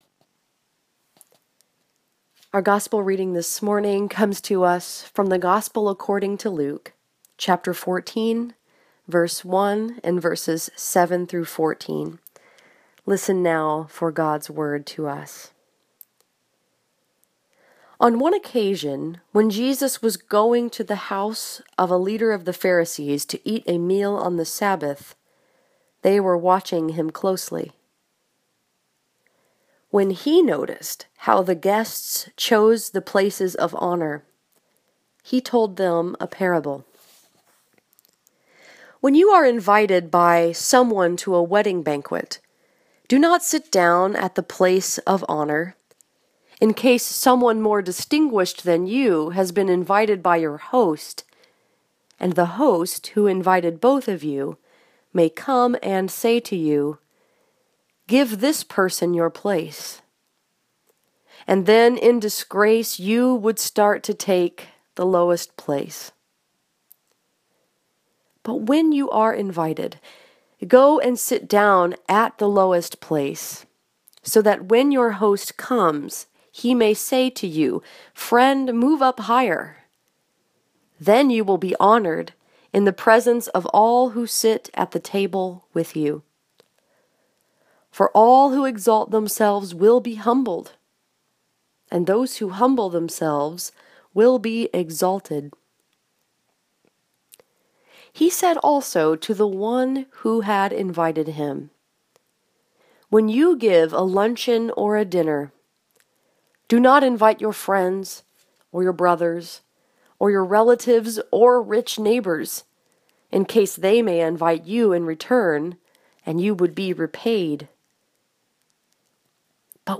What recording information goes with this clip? This sermon was preached at Crossroads Presbyterian Church in Walled Lake, Michigan and was focused upon Luke 14:1, 7-14.